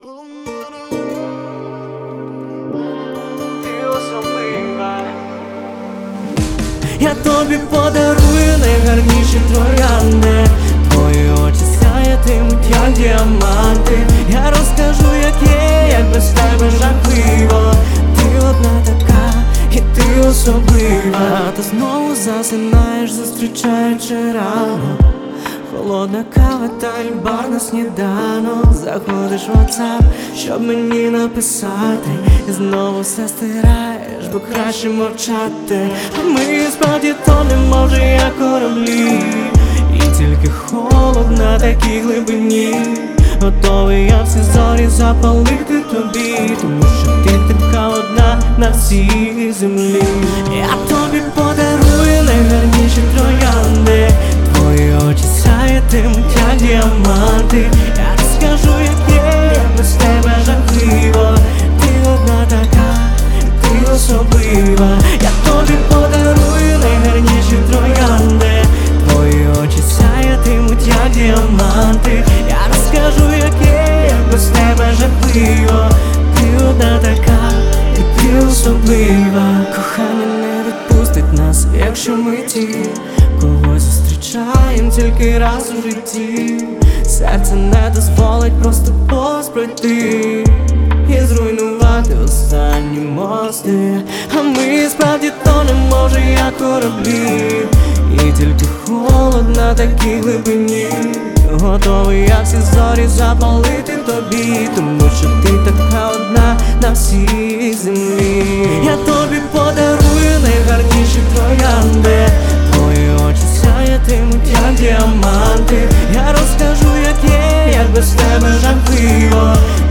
• Жанр: Українські пісні